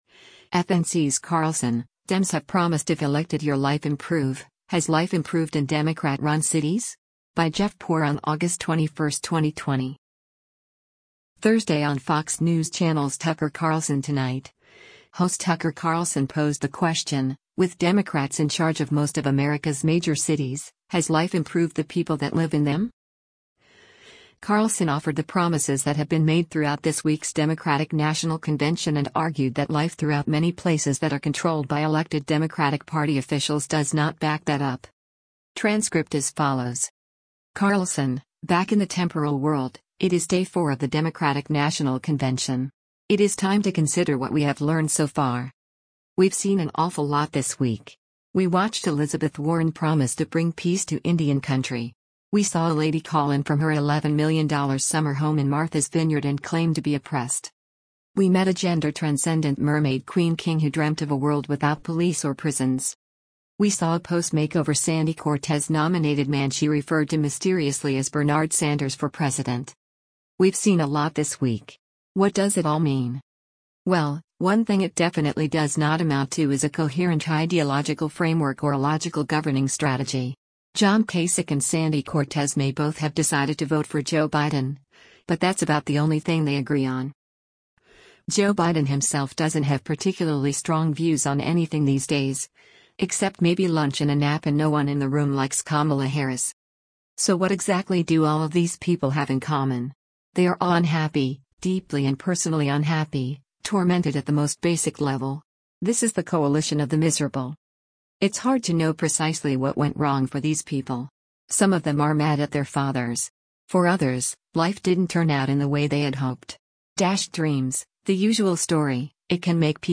Thursday on Fox News Channel’s “Tucker Carlson Tonight,” host Tucker Carlson posed the question: With Democrats in charge of most of America’s major cities, has life improved the people that live in them?